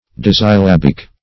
Search Result for " dissyllabic" : The Collaborative International Dictionary of English v.0.48: Dissyllabic \Dis`syl*lab"ic\ (d[i^]s`s[i^]l*l[a^]b"[i^]k), a. [Cf. F. dissyllabique.